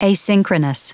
Prononciation du mot asynchronous en anglais (fichier audio)